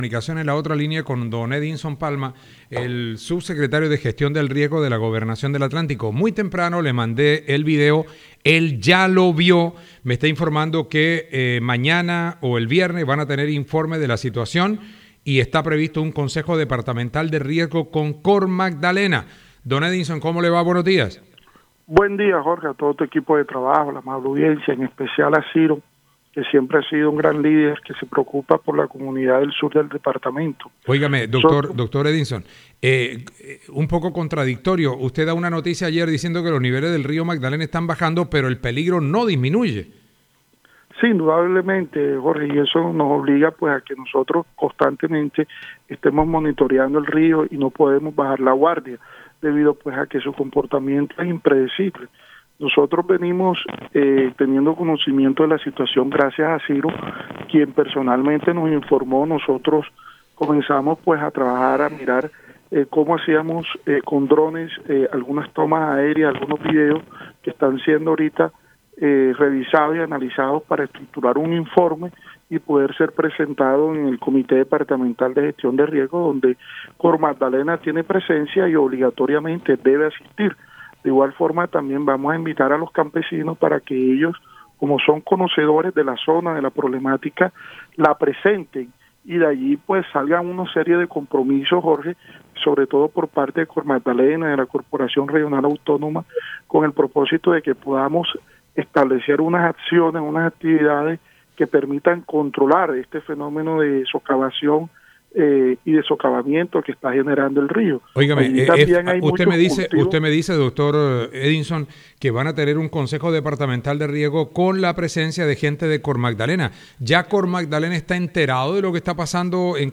Edison Palma, Sub Secretario de Gestión del Riesgo